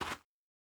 Bare Step Gravel Hard E.wav